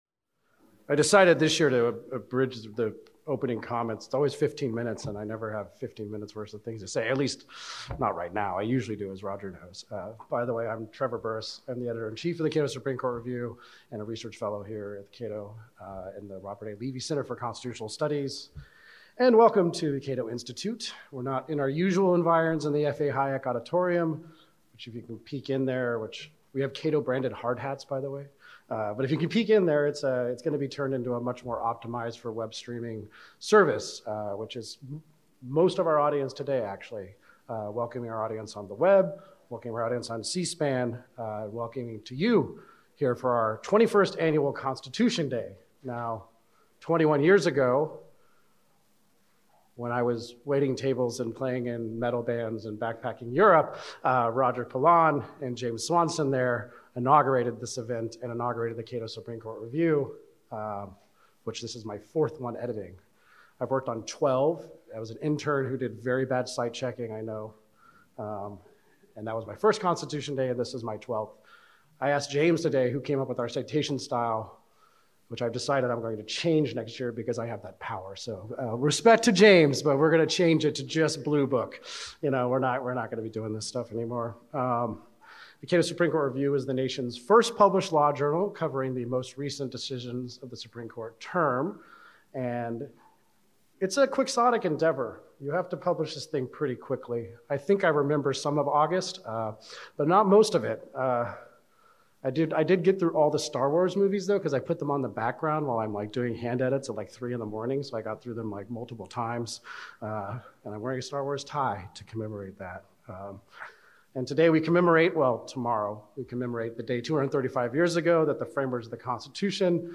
21st Annual Constitution Day: Panel I: Constitutional Structure
We celebrate that event each year with the release of the new issue of the Cato Supreme Court Review and with a day‐long symposium featuring noted scholars discussing the recently concluded Supreme Court term and the important cases coming up.